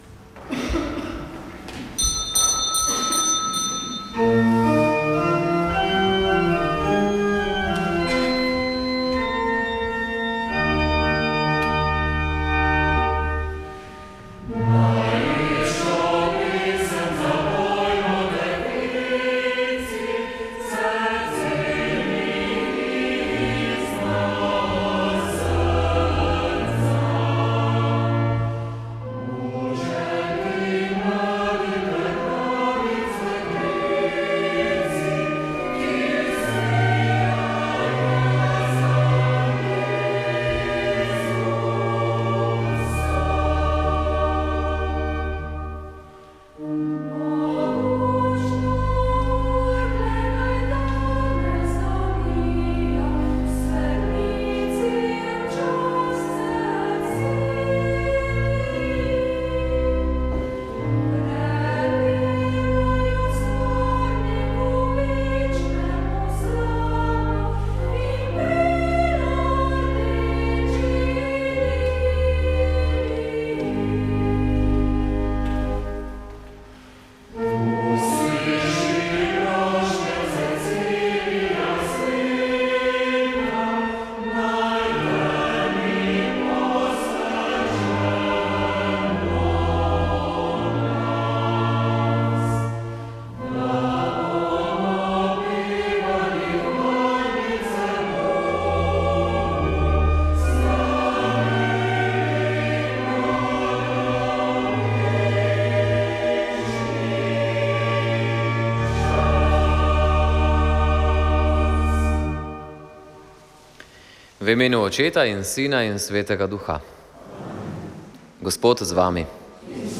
Sveta maša
Sv. maša iz bazilike Marije Pomagaj na Brezjah 17. 5.